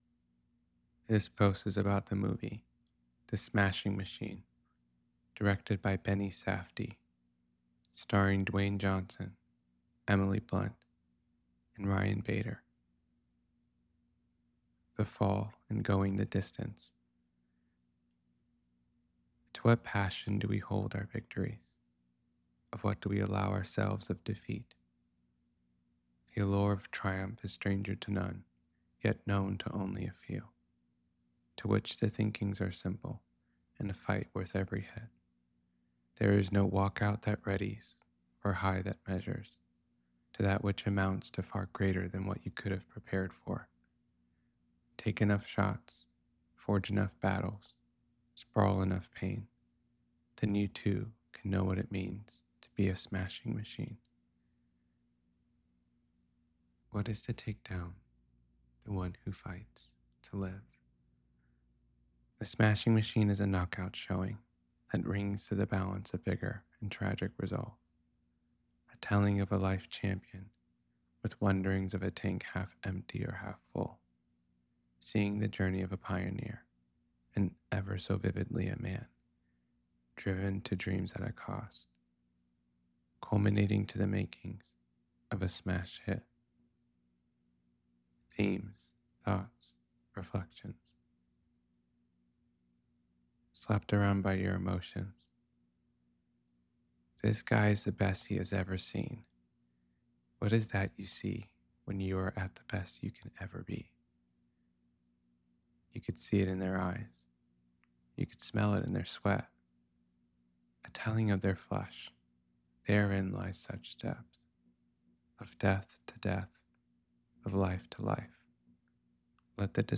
the-smashing-machine-to-know-a-story-reading.mp3